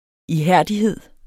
Udtale [ iˈhæɐ̯ˀdiˌheðˀ ]